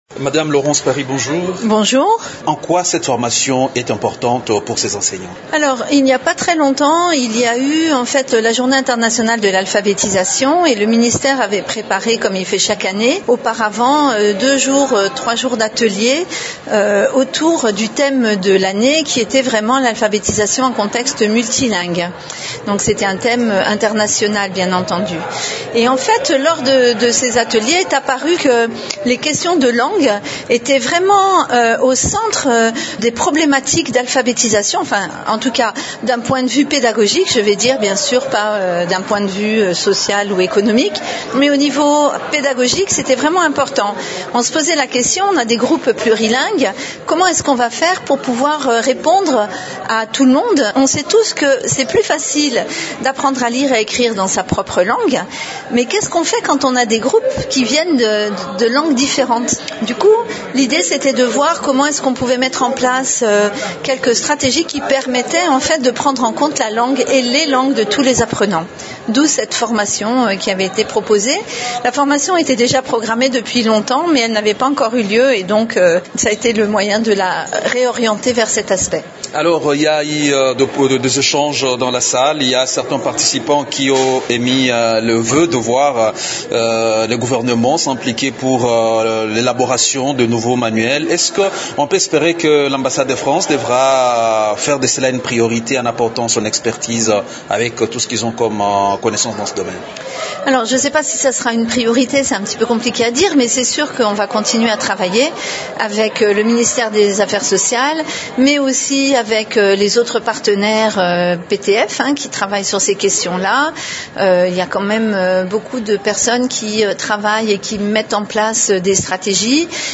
est l’invitée de Radio Okapi.